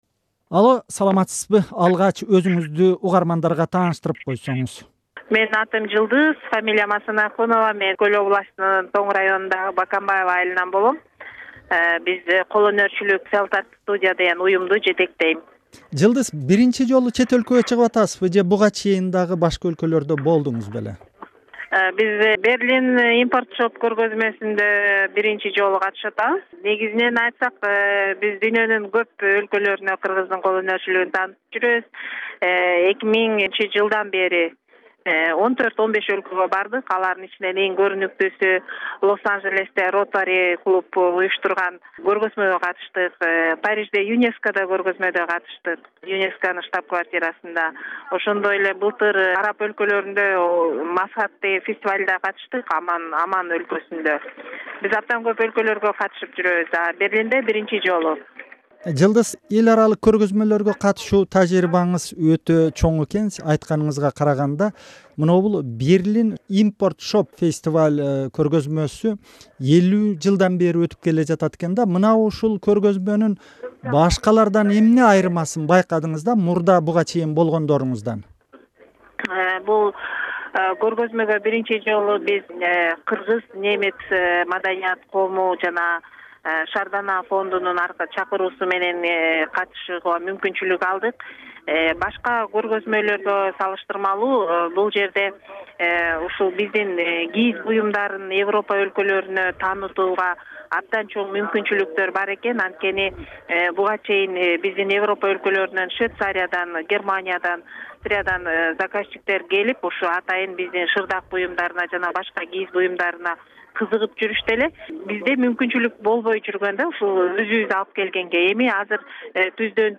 Эл аралык Import Shop Berlin көргөзмөсүнө катышкан кыргызстандык уз айымдар менен маек -1
Germany, Import Shop Berlin Market, Handcrafters from Kyrgyzstan, Nov 11, 2011